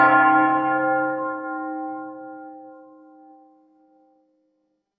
Church Bell Toll
A deep, resonant church bell tolling once with rich overtones and long decay
church-bell-toll.mp3